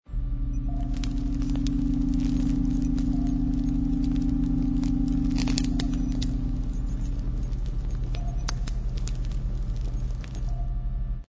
Sound Effects (SFX) were created to paint the scene inside and surrounding the Submarine.
Snapping Shrimp
SND_NAT7_SNAPPING_SHRIMP_10_sec.mp3